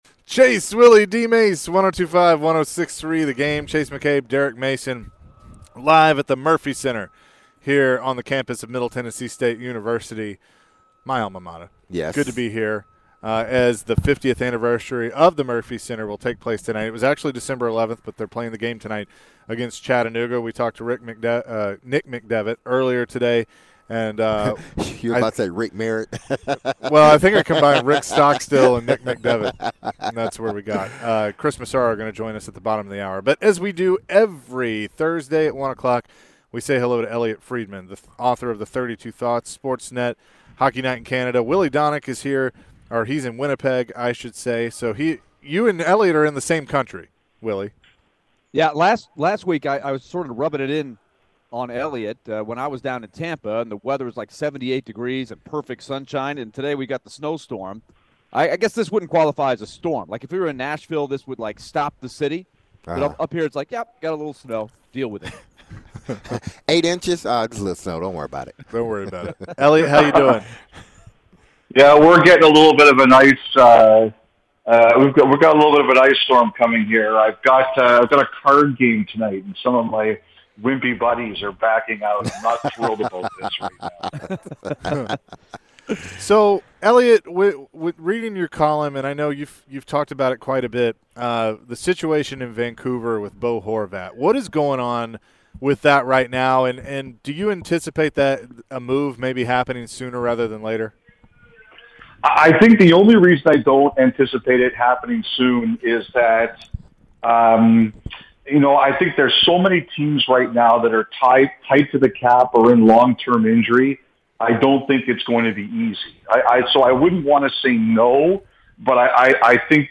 Elliotte Friedman Interview (12-15-22)